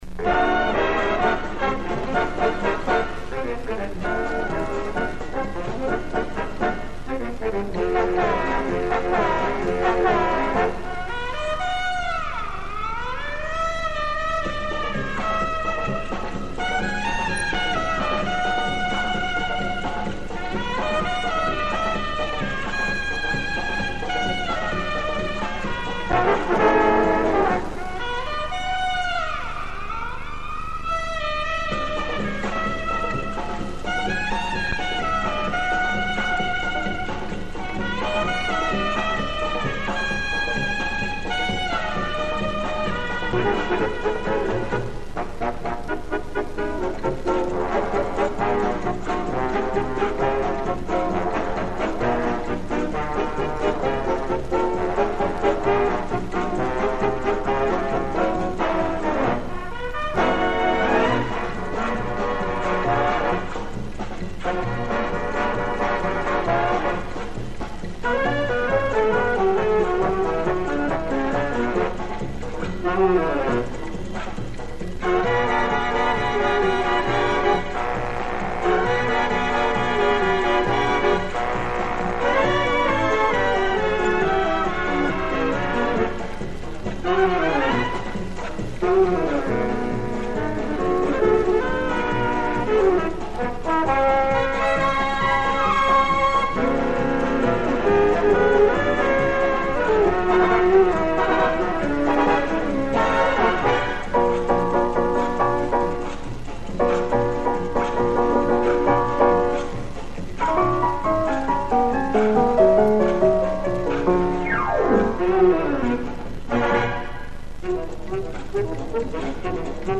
Давным-давно с эфира записал замечательное попурри